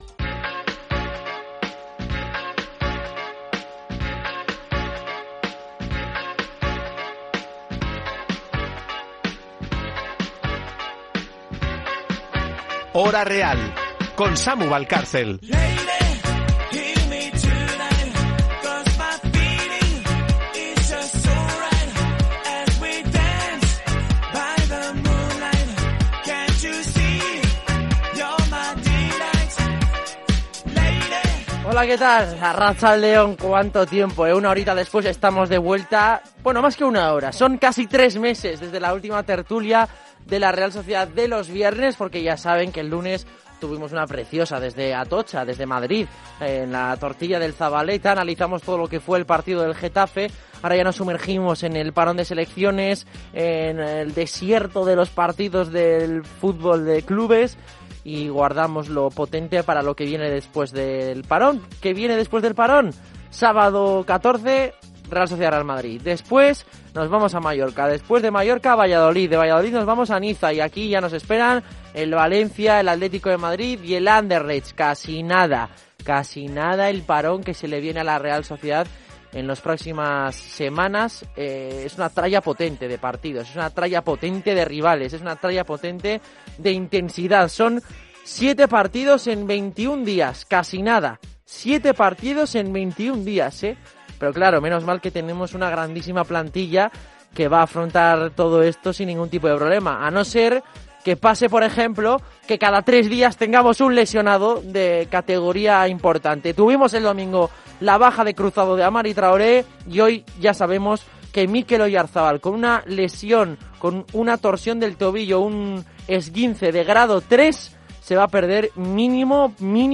Tertulias